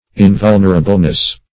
Invulnerableness \In*vul"ner*a*ble*ness\, n.
invulnerableness.mp3